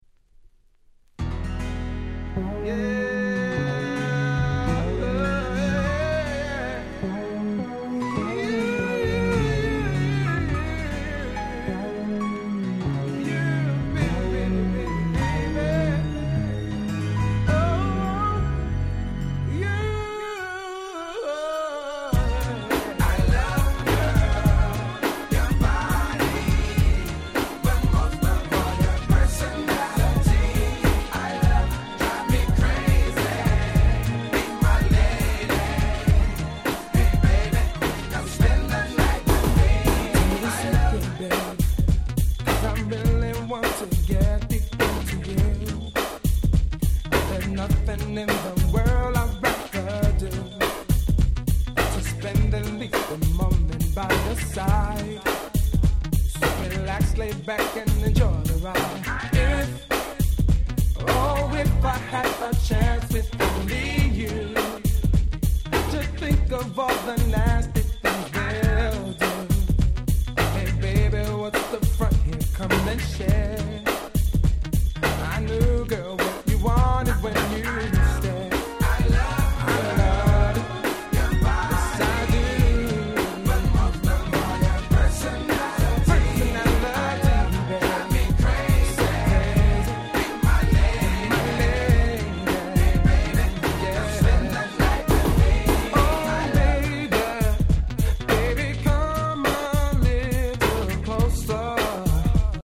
92' Nice New Jack Flava R&B !!
ホントこの時代のR&Bって良いですよね〜。